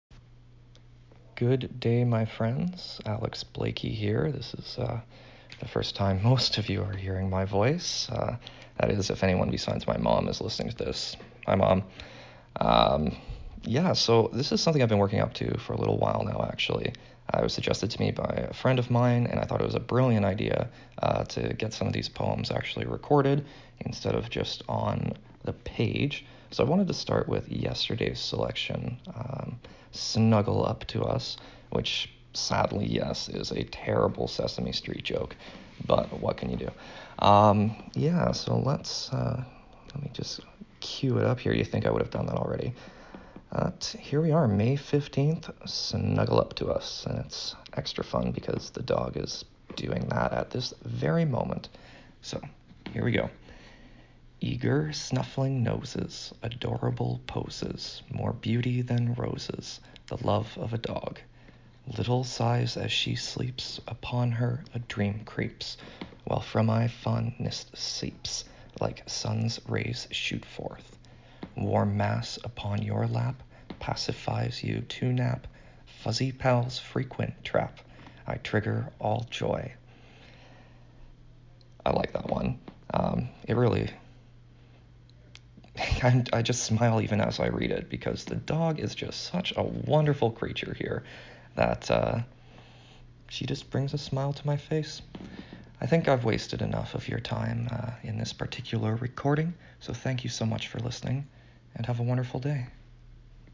Your voice adds an extra dimension to your poem…no one captures the intended cadence better that the author.
snuggleuptous-poem-only.mp3